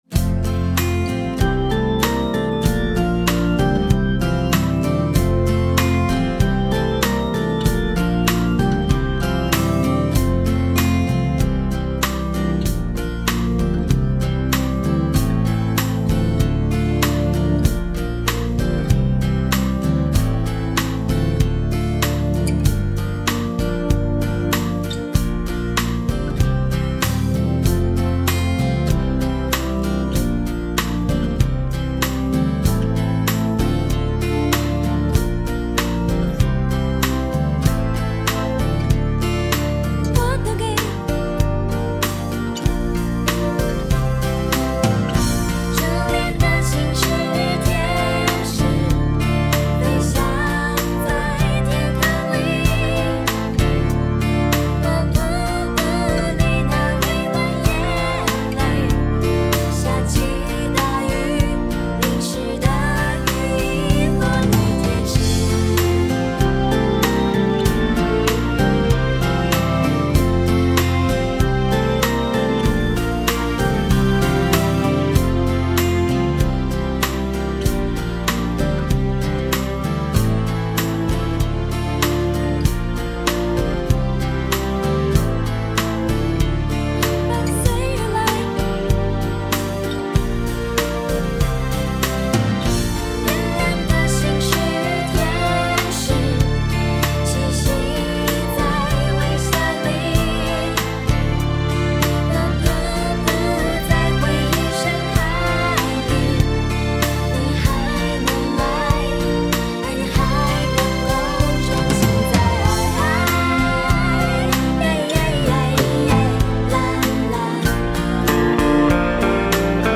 伴奏版本